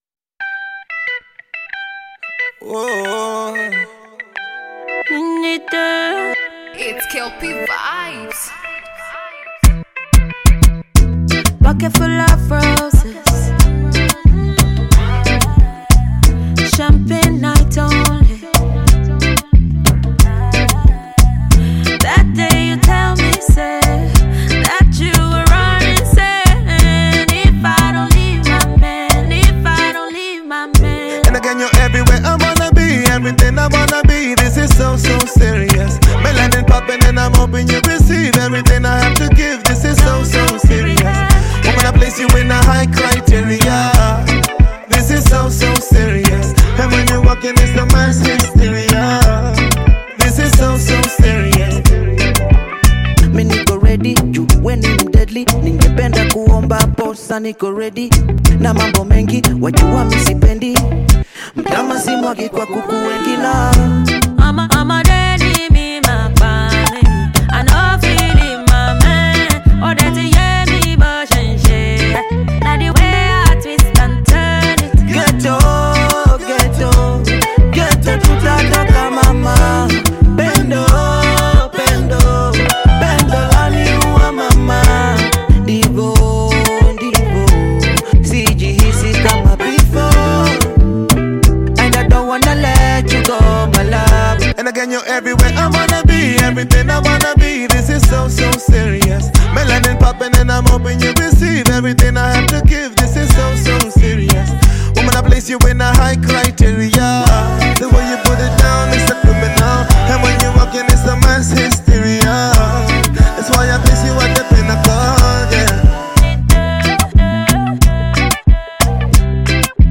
songstress
pop band